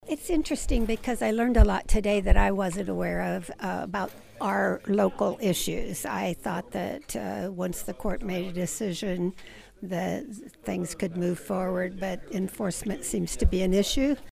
66th District State Representative Syndey Carlin expressed a common sentiment on the laws regarding these properties: